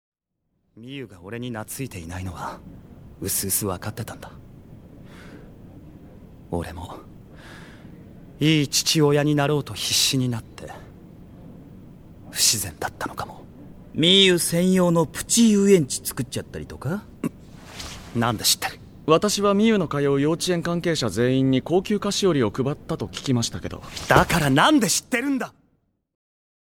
本編で描かれなかった東吾＆朝霞の甘い結婚式を、豪華声優陣による魅惑のヴォイスで紡ぐ。
高塚東吾：谷山紀章
あと声のトーンが落として、低いほうになりました。